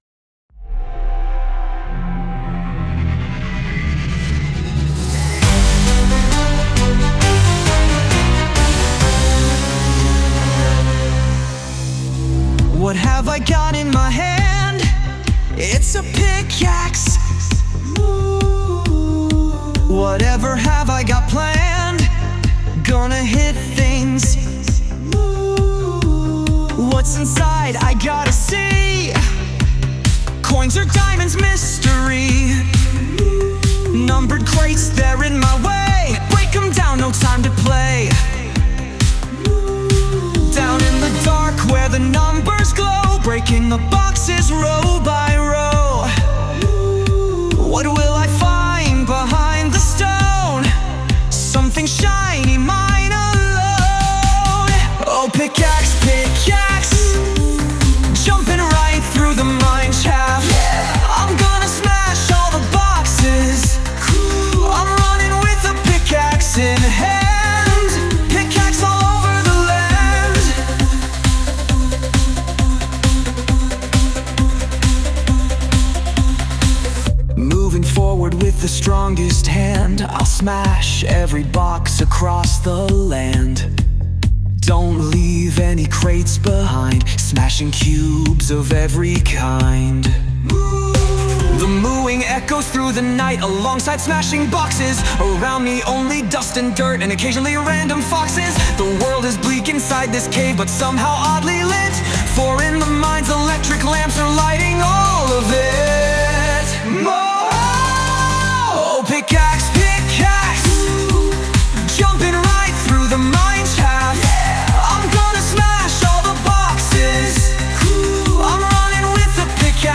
Lyrics : Started by me, but with a lot of ChatGPT
Sung by Suno